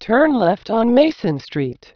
A demonstration of the speech quality with implanted prosody is available in waveform file ([WAVE stltts1.wav and stltts2.wav]) on the conference CD.
audio file demonstrating quality of synthesis method
Tech. description: sample rate = 11025, mono, linear encoding, 16 bits per sample